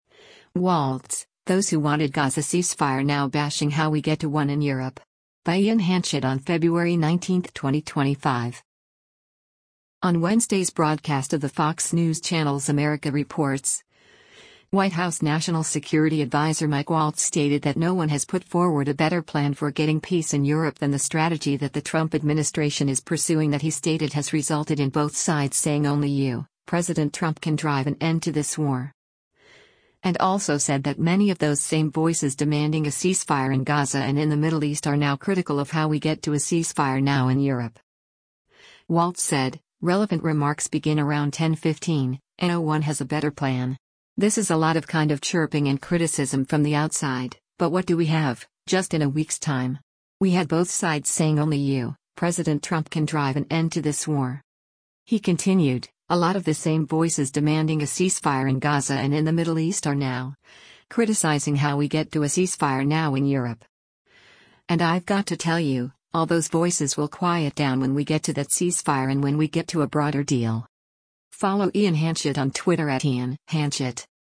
On Wednesday’s broadcast of the Fox News Channel’s “America Reports,” White House National Security Adviser Mike Waltz stated that no one has put forward a better plan for getting peace in Europe than the strategy that the Trump administration is pursuing that he stated has resulted in “both sides saying only you, President Trump can drive an end to this war.”